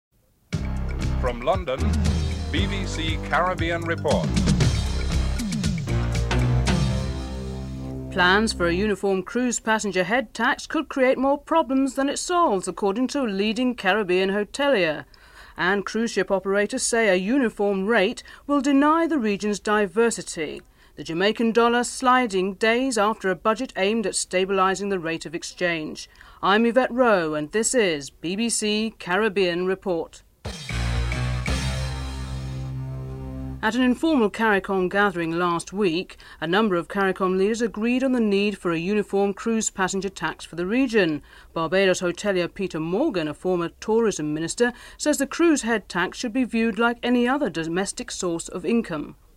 1. Headlines (00:00-00:40)